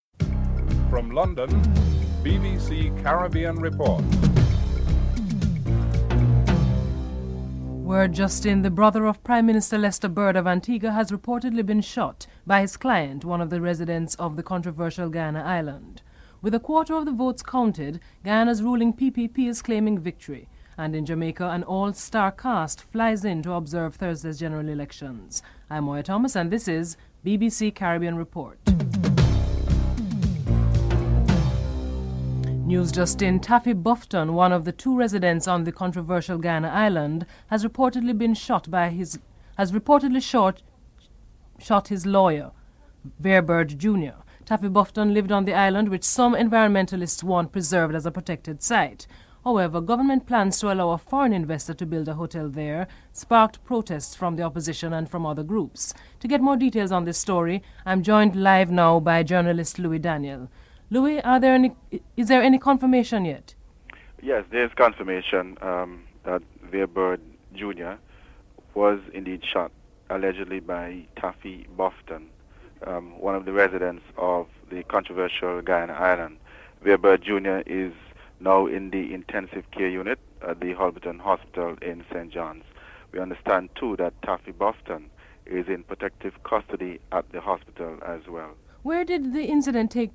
1. Headlines (00:00-00:31)
Jamaica's Ambassador to Washington Richard Burnell is interviewed (12:16-15:28)